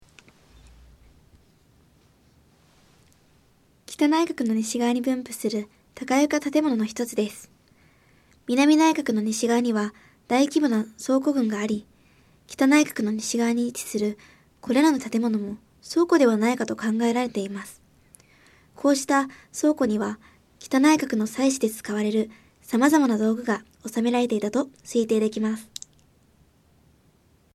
こうした倉庫には北内郭の祭祀で使われる様々な道具が納められていたと推定できます。 音声ガイド 前のページ 次のページ ケータイガイドトップへ (C)YOSHINOGARI HISTORICAL PARK